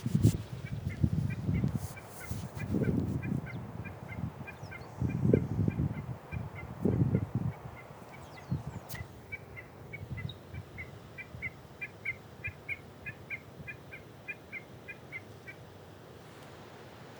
Becasina Patagónica (Gallinago magellanica)
Nombre en inglés: Magellanic Snipe
Localización detallada: Dique Brisoli
Condición: Silvestre
Certeza: Observada, Vocalización Grabada